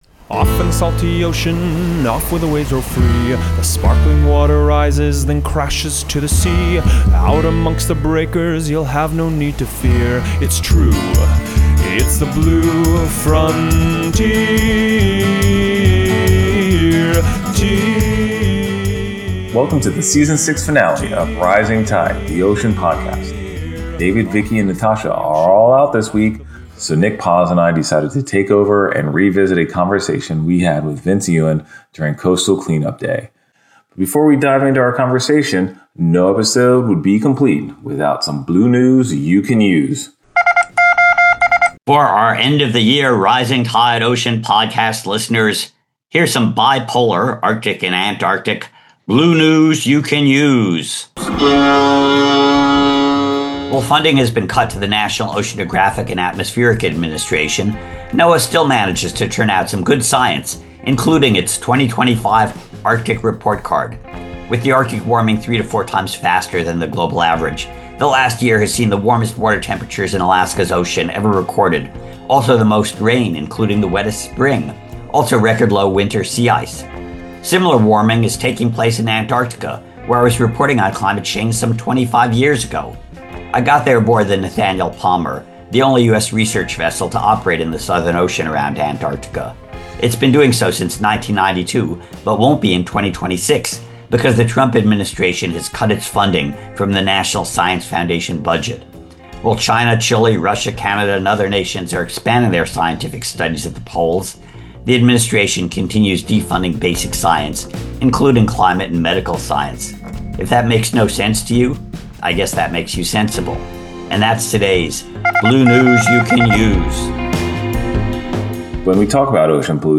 on-the-ground (actually on the beach) interview
During a beach clean-up on the city’s oceanfront he discusses the significance of community-led trash cleanups in protecting the environment.